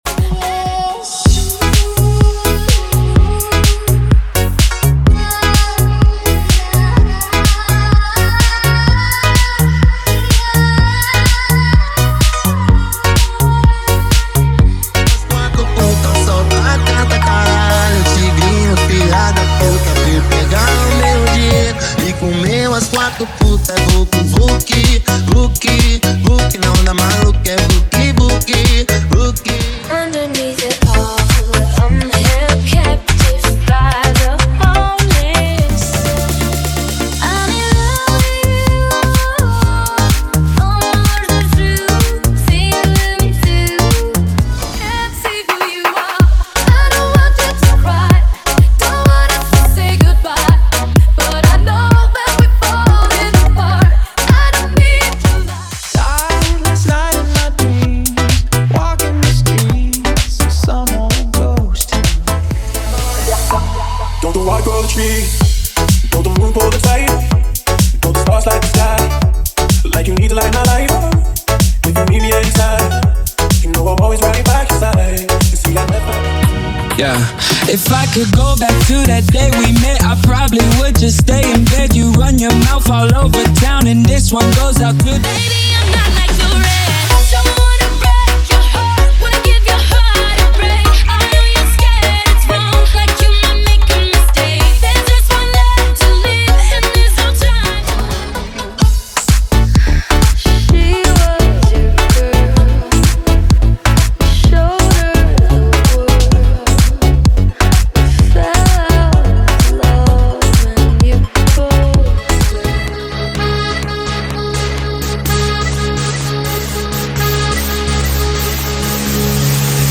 Sem Vinhetas
Em Alta Qualidade